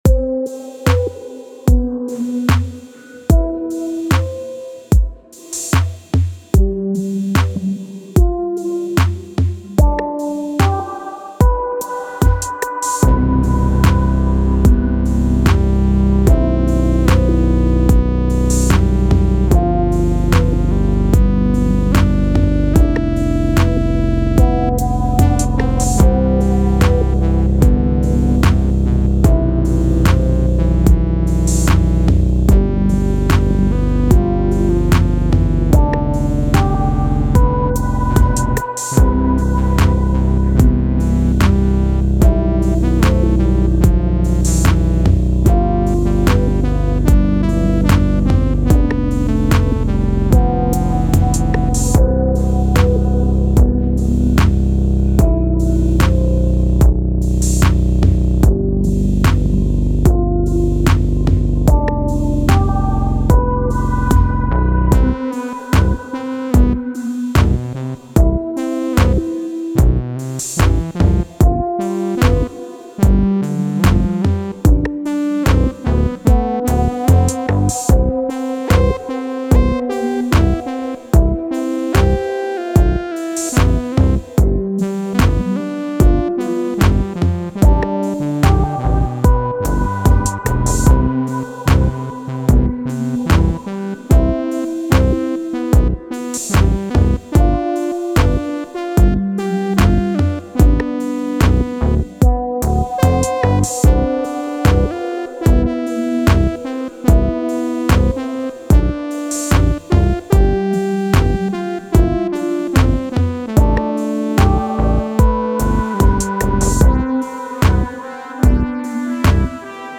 Improvising with a keyboard over a beat.